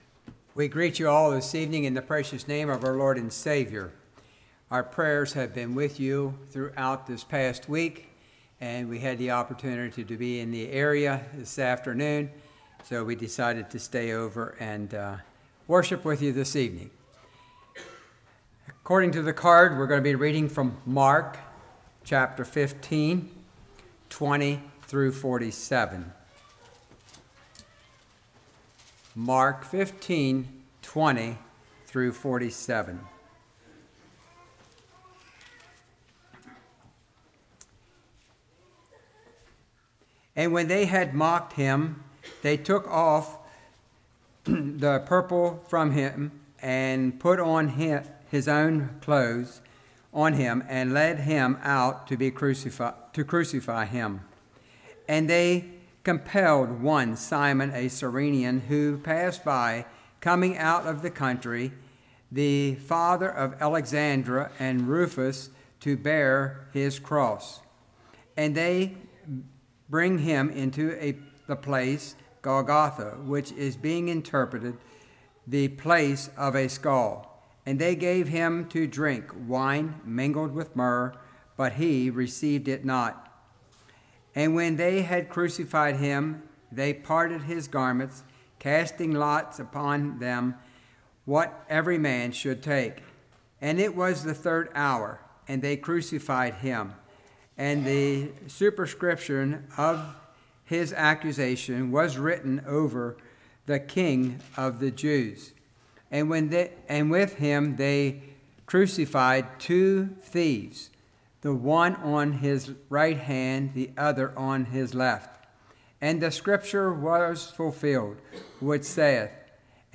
Passage: Mark 15:20-47 Service Type: Revival